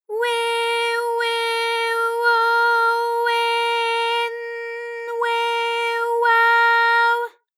ALYS-DB-001-JPN - First Japanese UTAU vocal library of ALYS.
we_we_wo_we_n_we_wa_w.wav